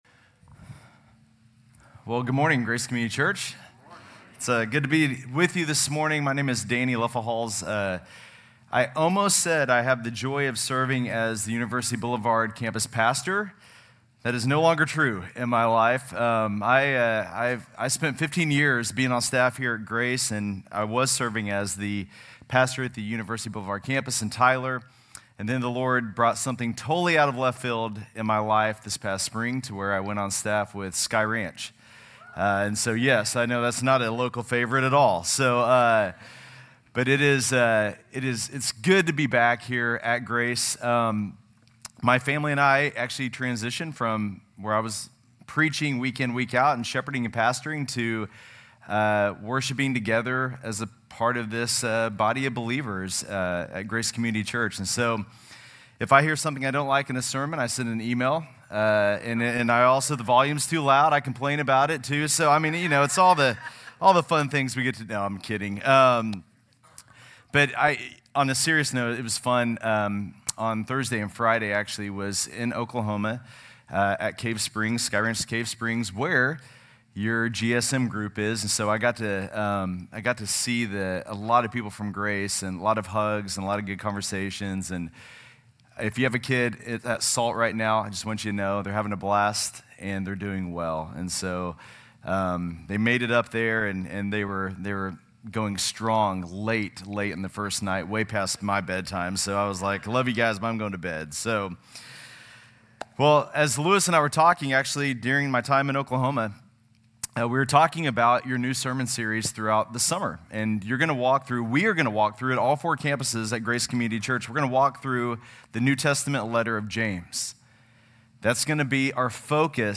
GCC-LD-June-11-Sermon.mp3